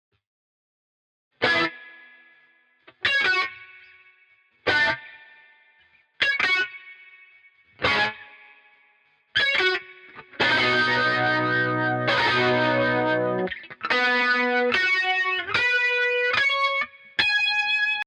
• Senza Delay: